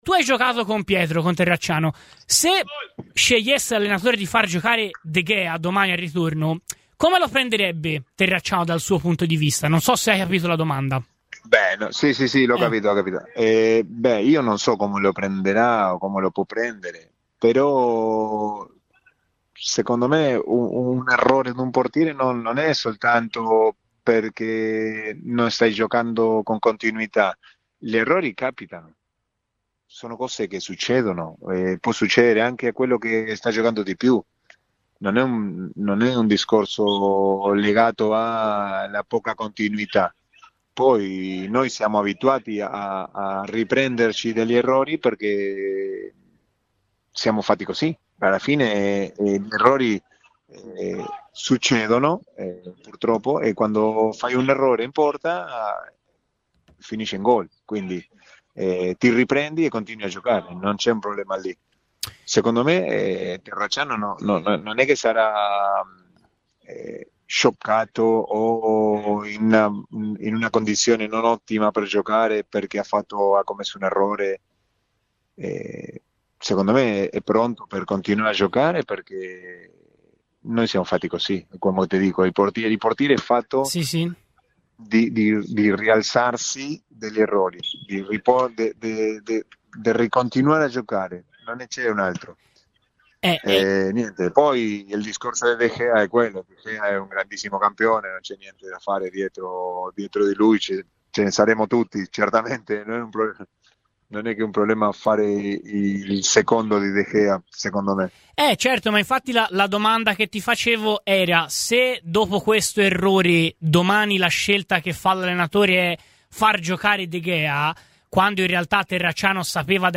In attesa della scelta finale, Radio FirenzeViola ha chiesto un parere a Mariano Andujar, ex portiere del Napoli nonché compagno di squadra di Terracciano ai tempi del Catania, con cui abbiamo discusso sul tipo di decisione che prenderà Palladino in merito: "Non so come la prenderebbe Pietro - afferma Andujar su un'eventuale esclusione di Terracciano dai titolari -, ma un errore di un portiere non arriva solo perché non sta giocando con continuità.